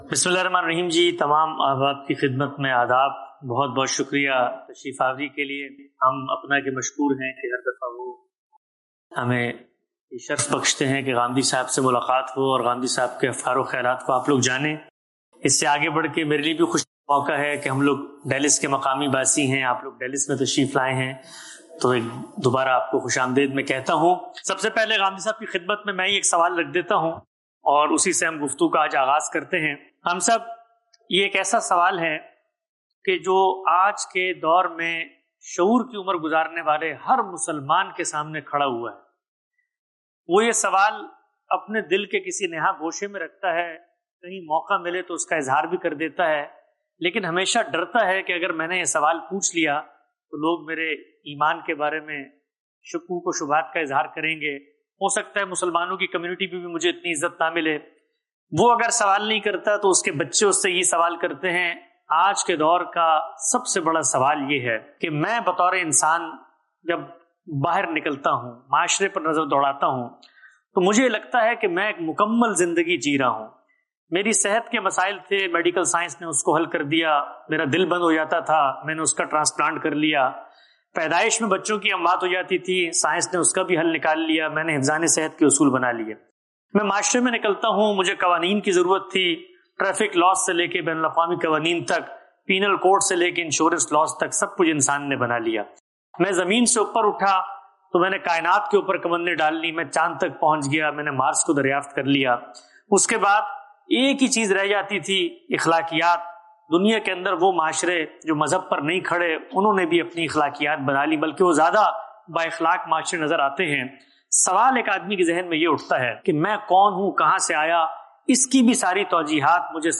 Javed Ahmad Ghamidi > Videos > Conversation- APPNA Convention 2023 - Questions & Answers Session with Javed Ahmed Ghamidi